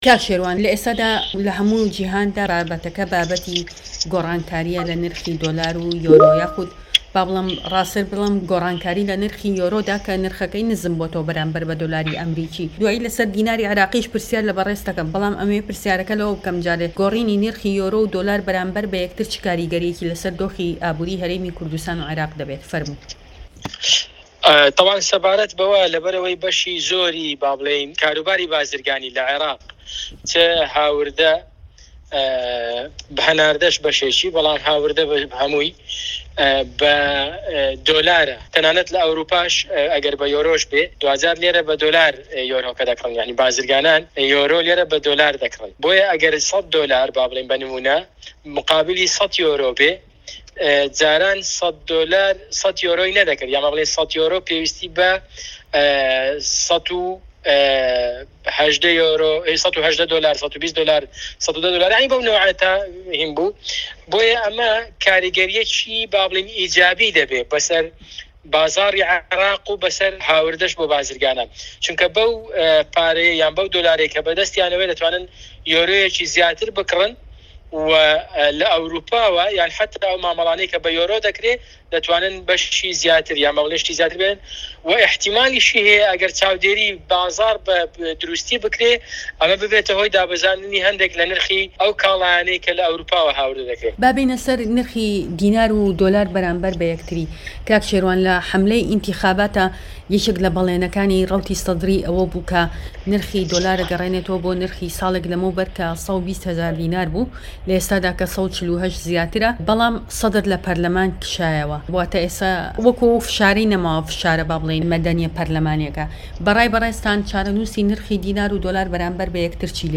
لەم وتووێژەدا